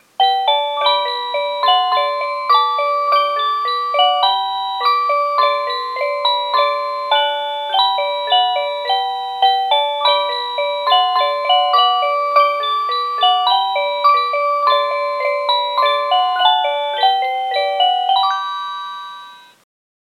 07-Cuckoo-Tune.mp3